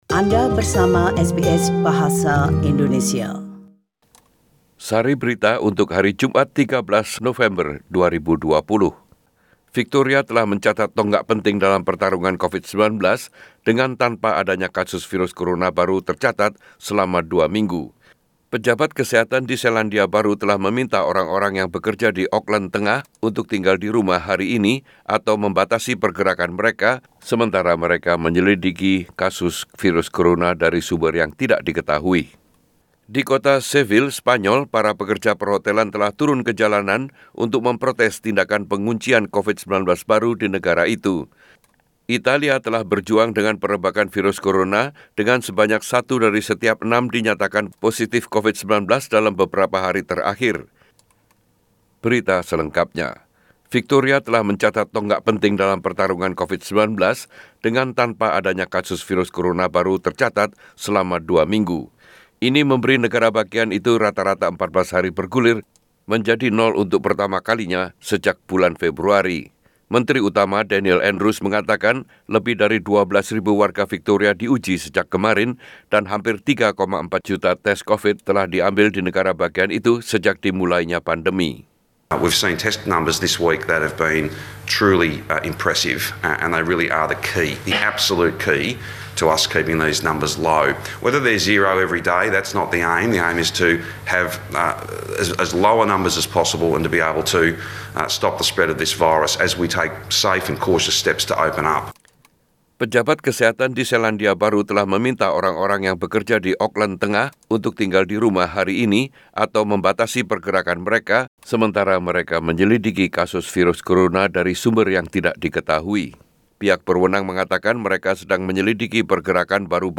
SBS Radio News in Bahasa Indonesia 13 November 2020